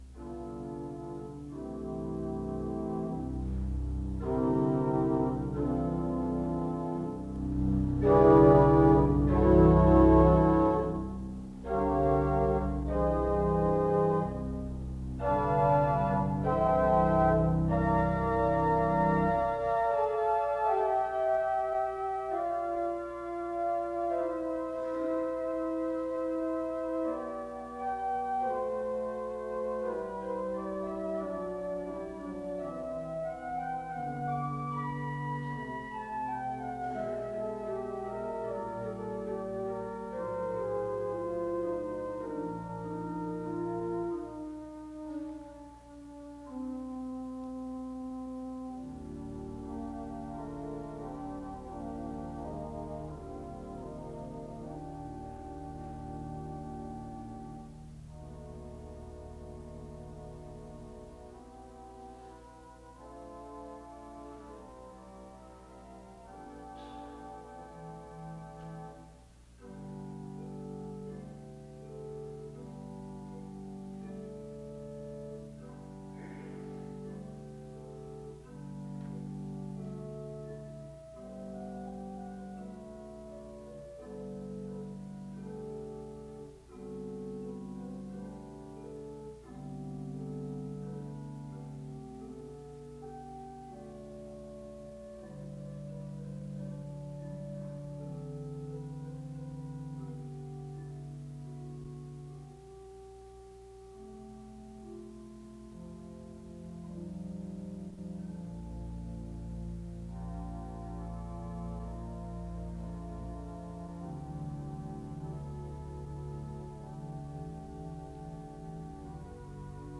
The service begins with music from 0:00-7:32. There is a responsive reading from 7:39-9:54. An introduction to the speaker is given from 10:00-11:48. A prayer is offered from 11:55-15:15.